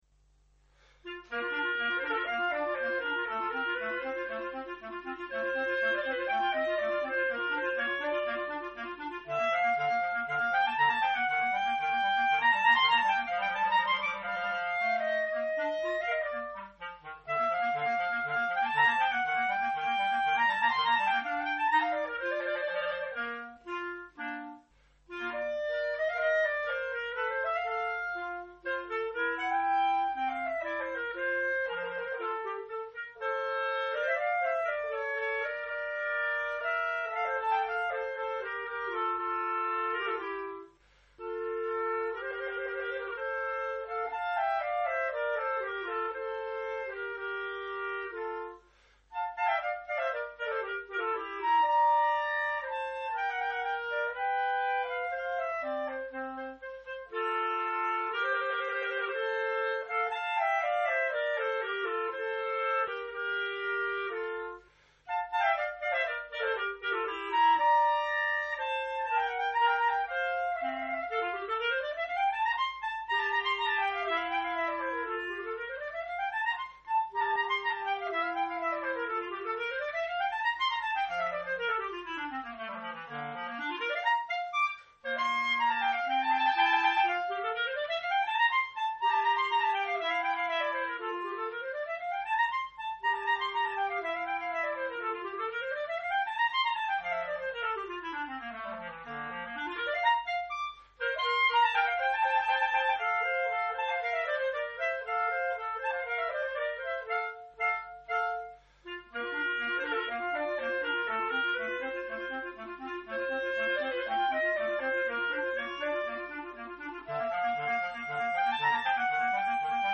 Duo N° 3 opus 5 en ut M.
1e mouvement, Allegro (5'01)